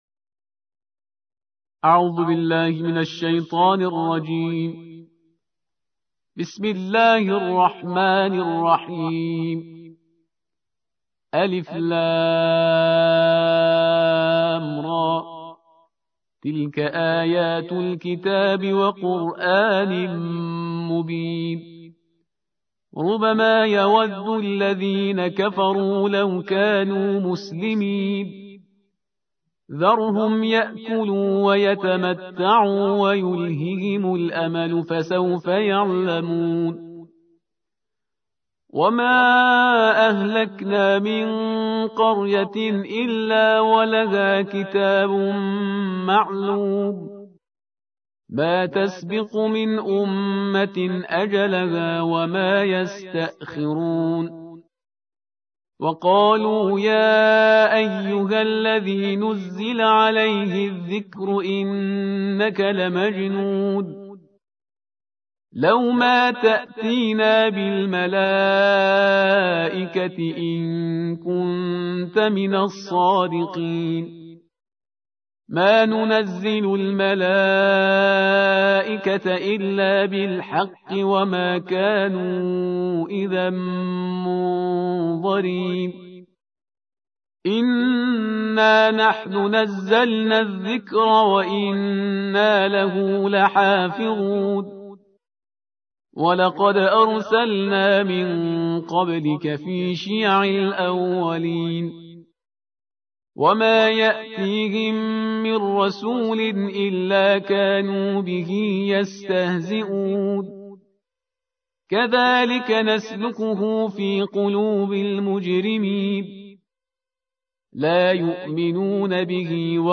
الجزء الرابع عشر / القارئ